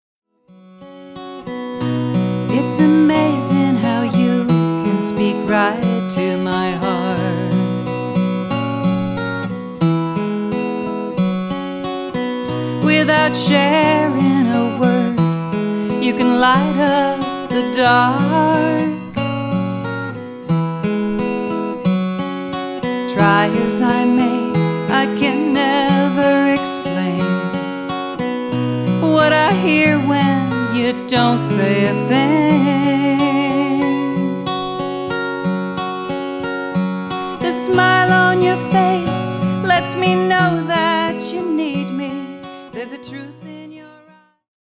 Country, Folk & Fun!